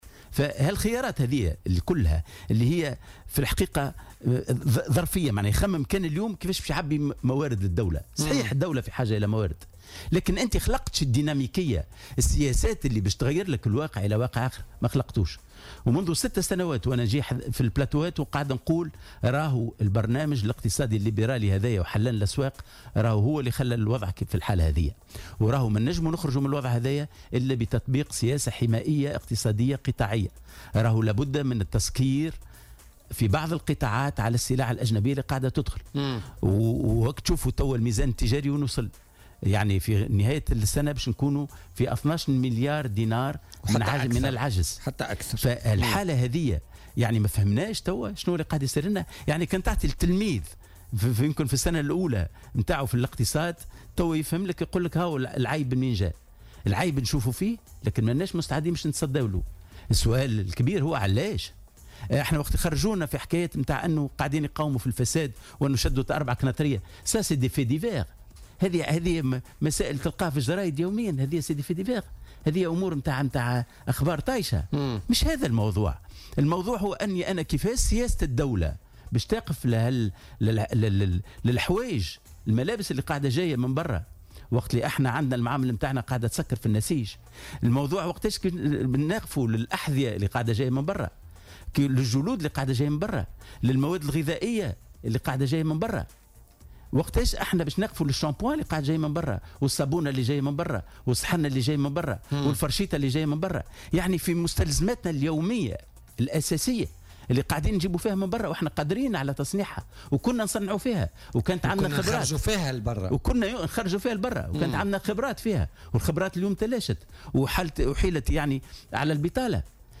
وأضاف في مداخلة له اليوم في برنامج "بوليتيكا" أنه يجب غلق الأبواب أمام التوريد في بعض القطاعات، خاصة مع تواصل انزلاق الدينار وتفاقم عجز الميزان التجاري.